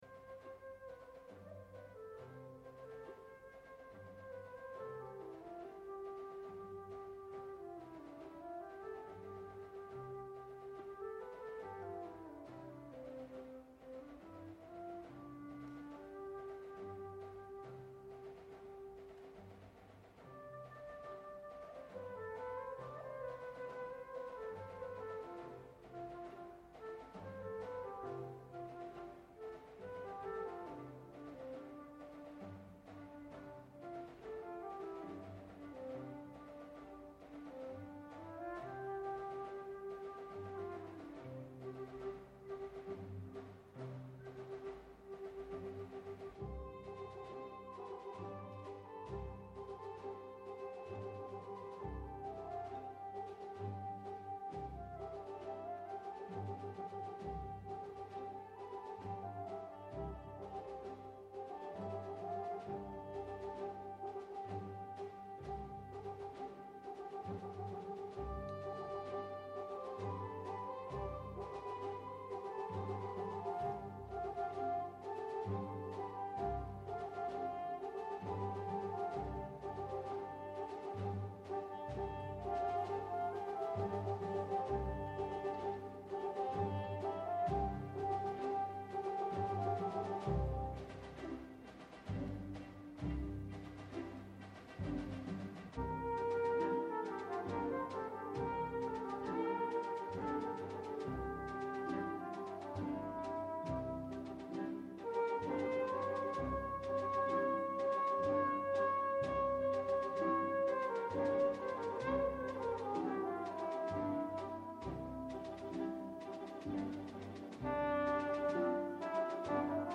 Καλεσμένη σήμερα η Μαίρη Ιγγλέση, Αντιδήμαρχος πολιτισμού Ηρακλείου Αττικής.